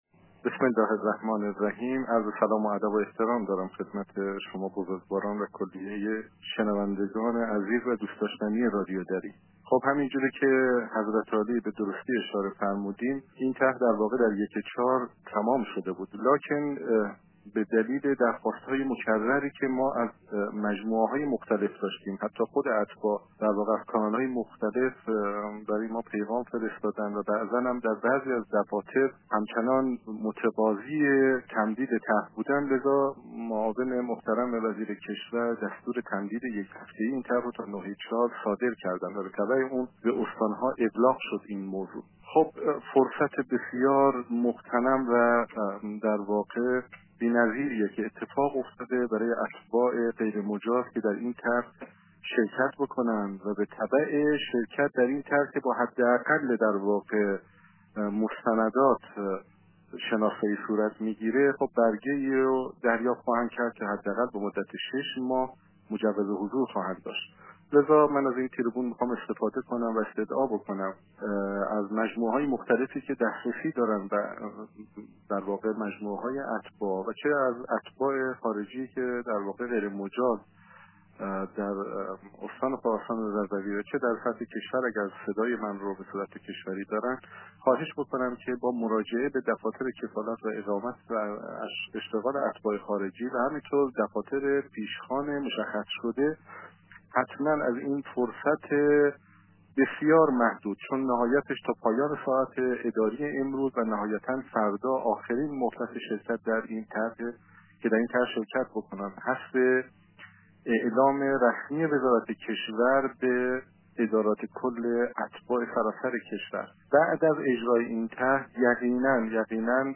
مشروح گفت و گو خبرنگار رادیو دری با سید علی قاسمی ، مدیرکل اتباع و مهاجرین خارجی استانداری خراسان رضوی را بشنوید.